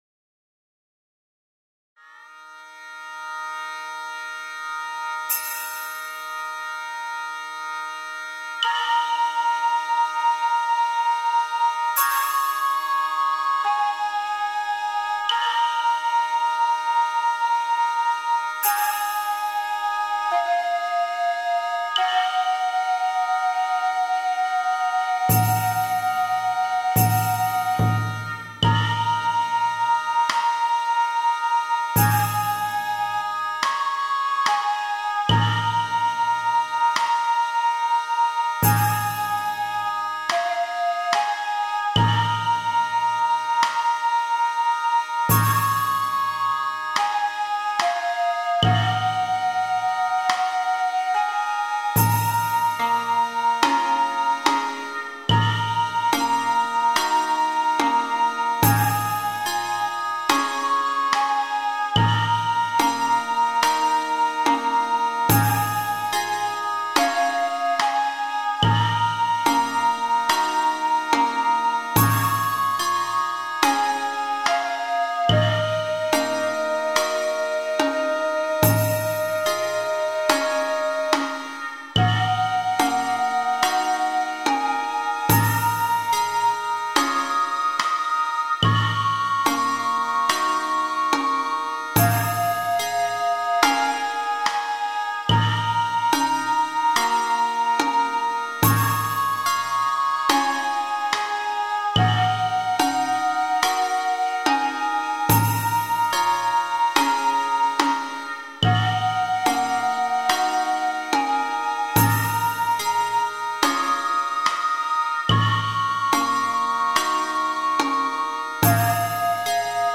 BGM
スローテンポ民族